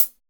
ROCK CHH F.wav